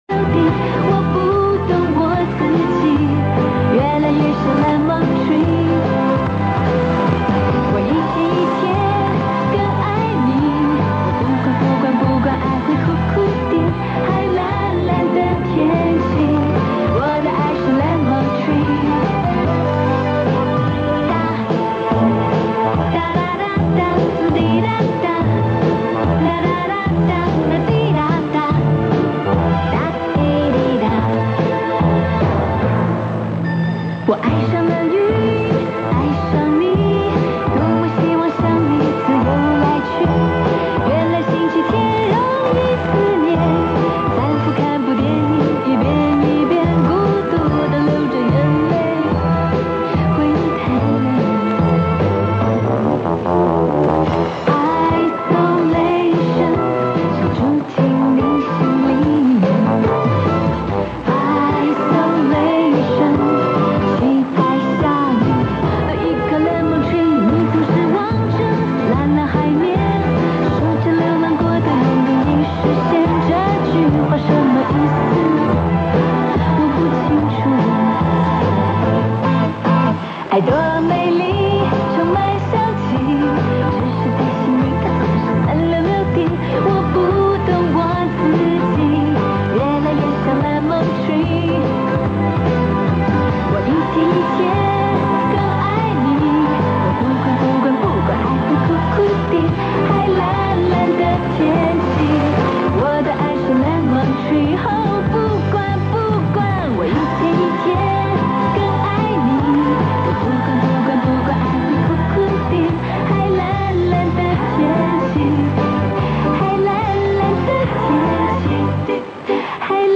这是本人录自电台的一组节目，由于录制于中波段，有些电磁干扰，见谅！